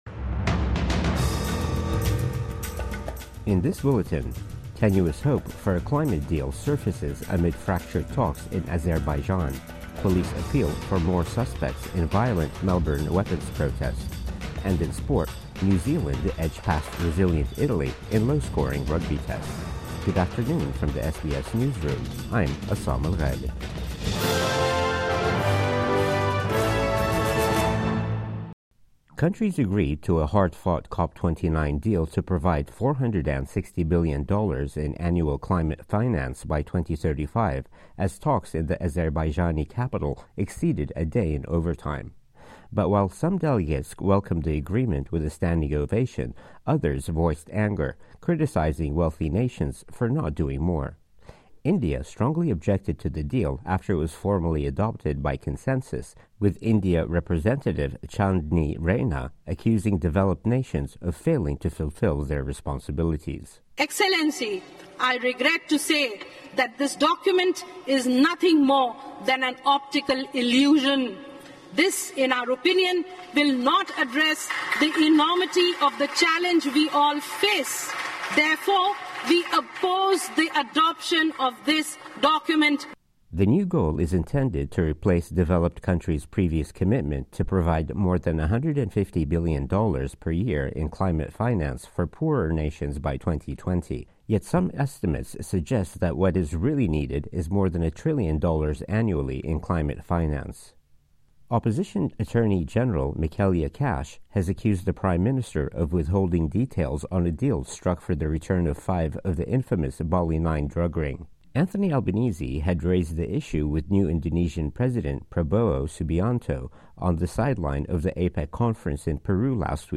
Midday News Bulletin 24 November 2024 5:39